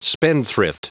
Prononciation du mot spendthrift en anglais (fichier audio)
Prononciation du mot : spendthrift